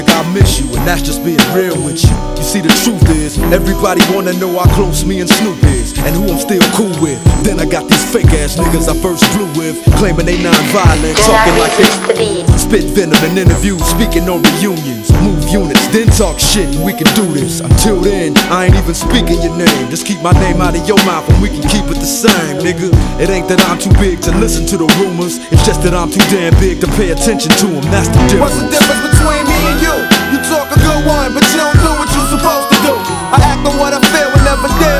Жанр: Иностранный рэп и хип-хоп / Рэп и хип-хоп
# Hip-Hop